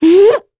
Talisha-Vox_Casting2_kr.wav